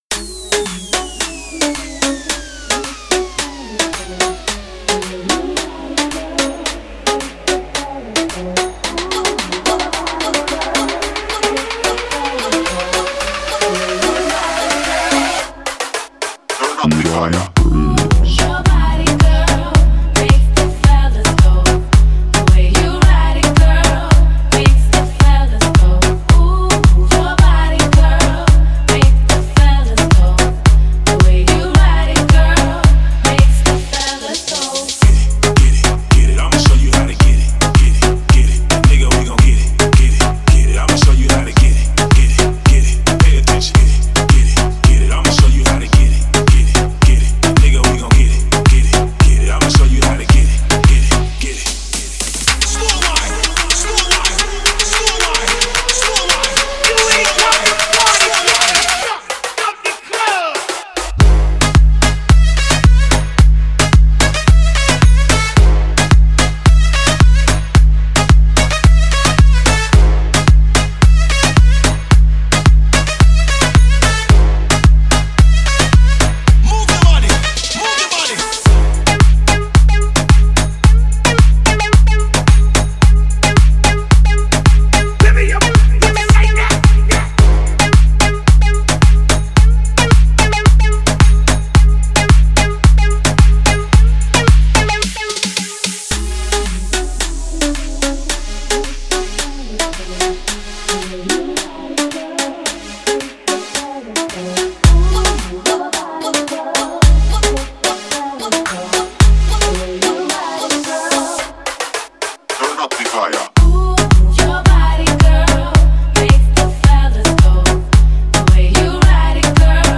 Download the new house remix for the club